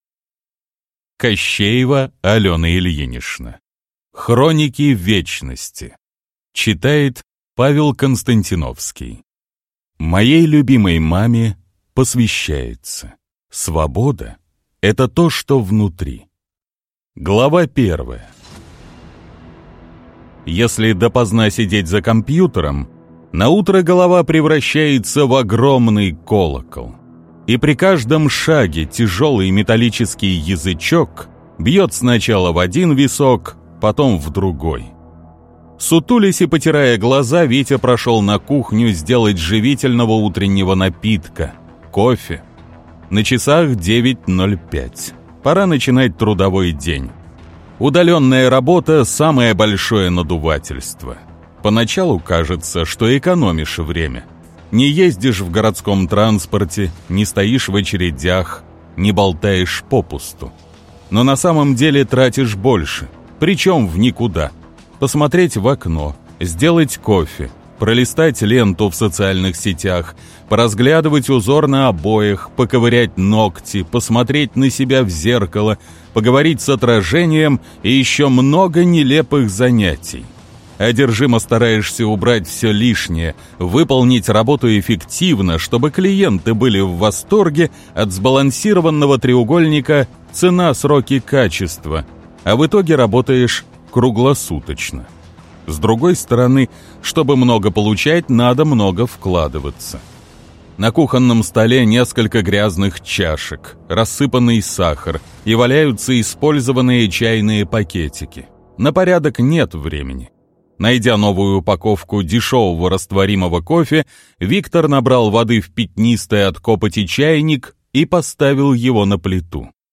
Аудиокнига Хроники Вечности | Библиотека аудиокниг